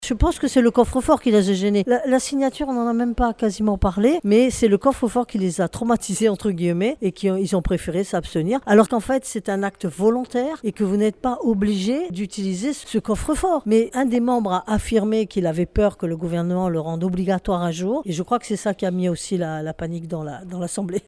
Séance-plénière-CESC-03.mp3